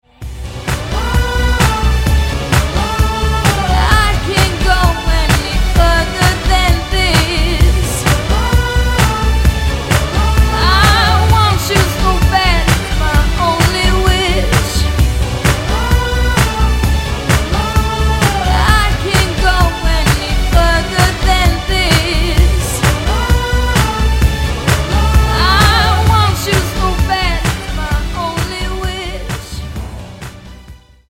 Хип-хоп команда из Лос-Анджелеса выпустила новый хит.